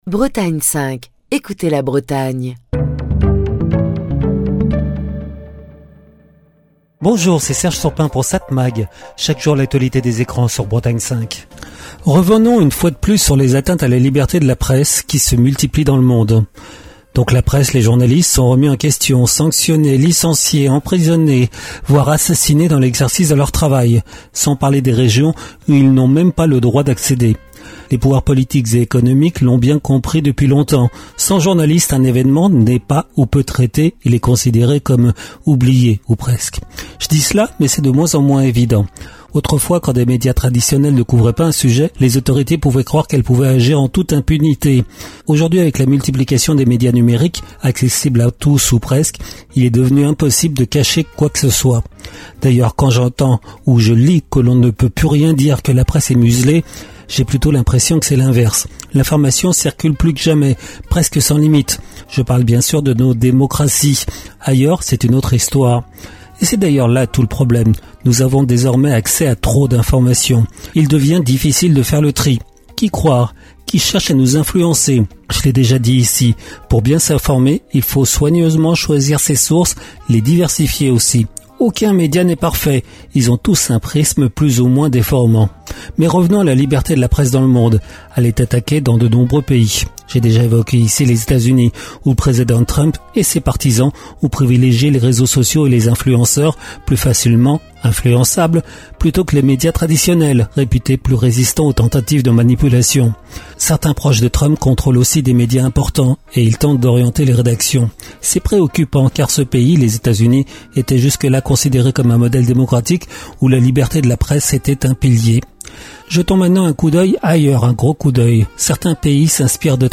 Chronique du 28 mai 2025.